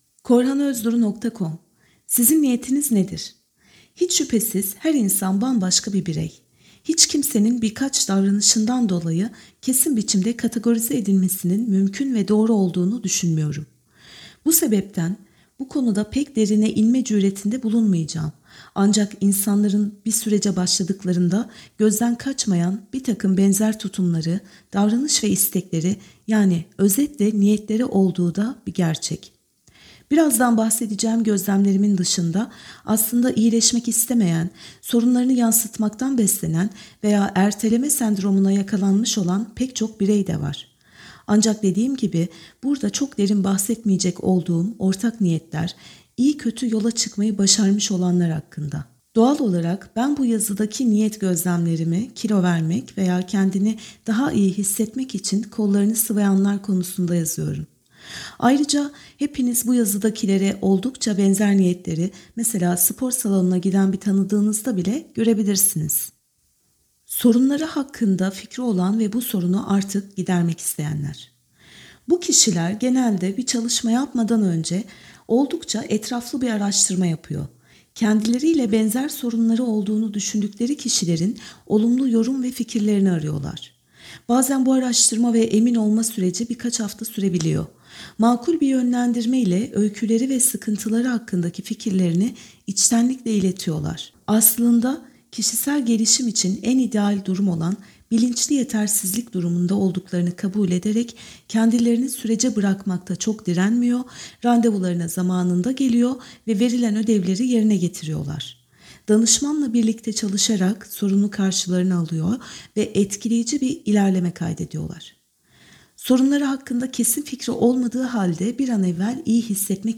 NiyetSesliOkuma.mp3